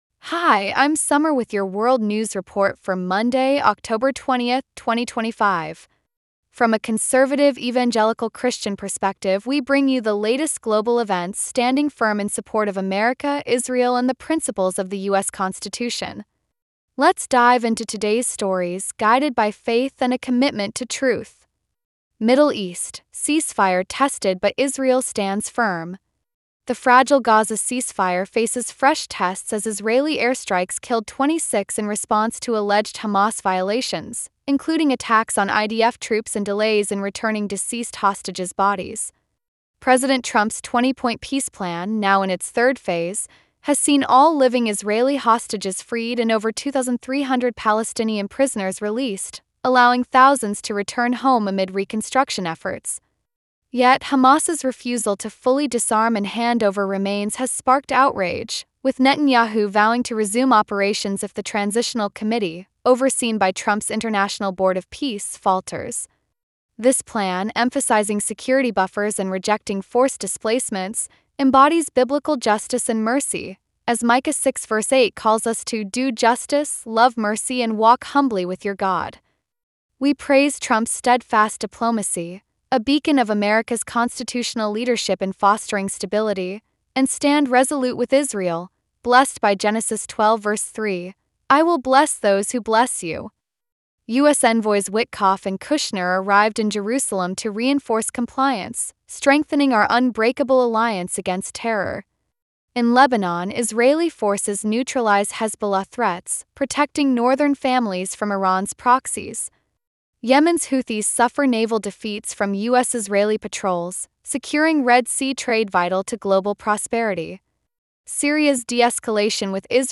World News Report